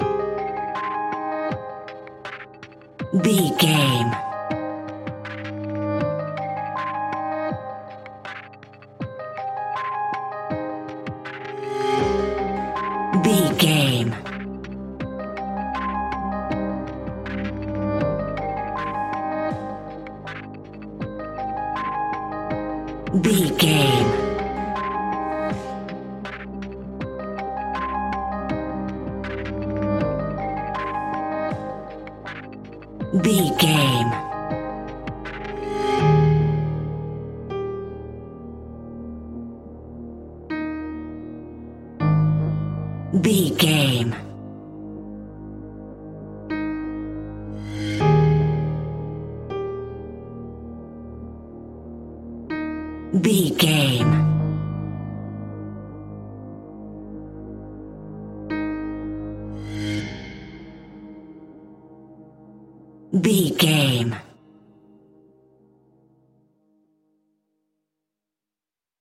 Aeolian/Minor
Slow
ominous
haunting
eerie
melancholy
ethereal
synthesiser
piano
strings
cello
horror music
Horror Pads